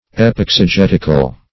Epexegetical \Ep*ex`e*get"ic*al\, a.